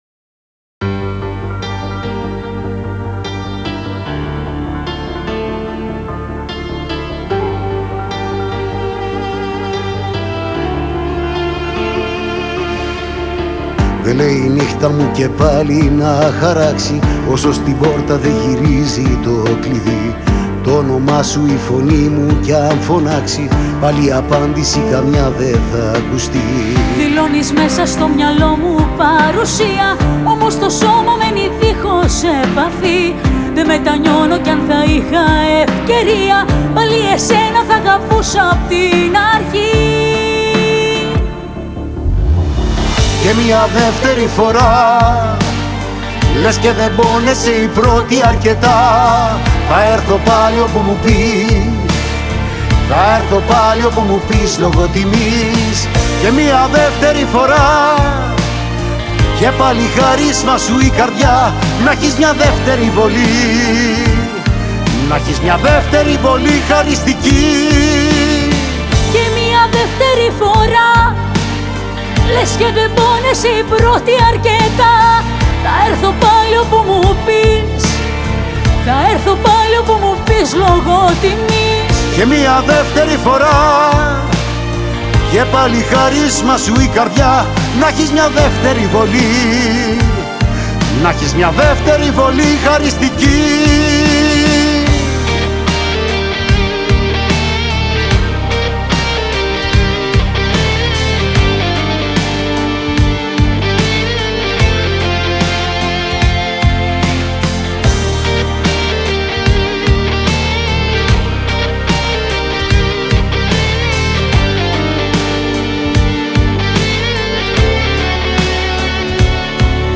μια ερωτική μπαλάντα